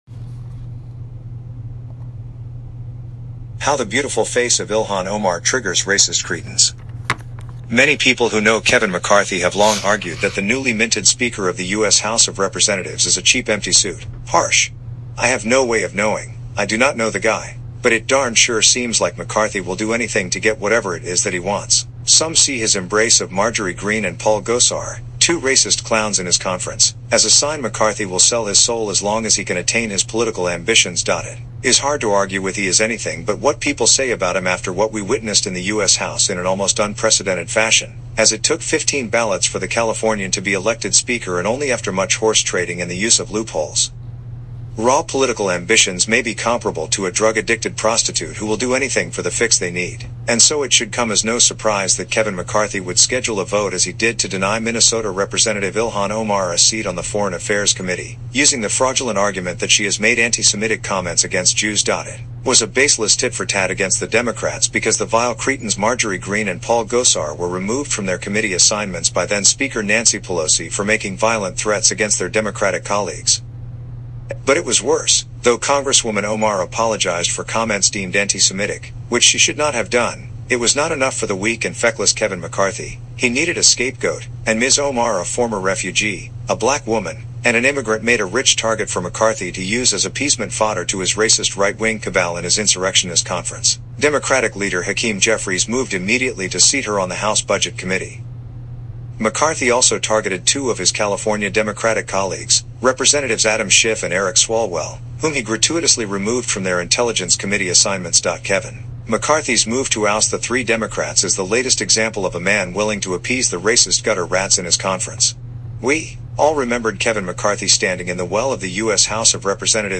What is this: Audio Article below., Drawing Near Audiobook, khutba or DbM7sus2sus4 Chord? Audio Article below.